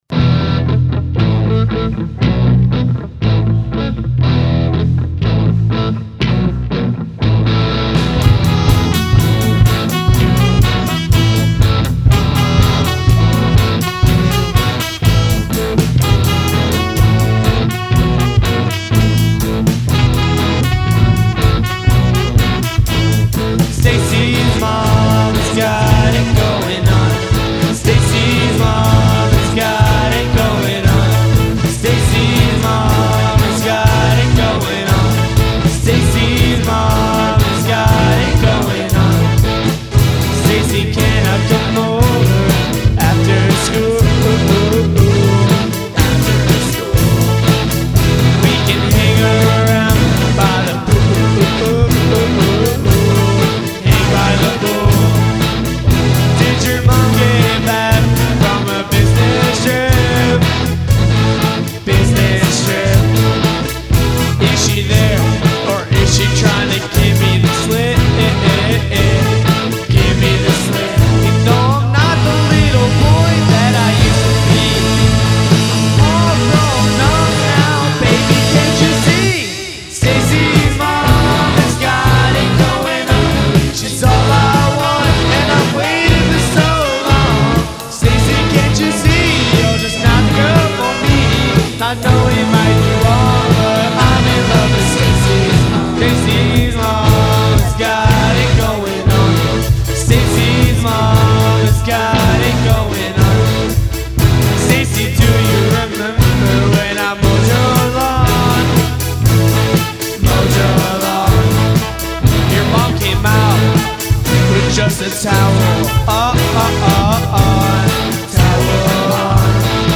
Studio Recording 2019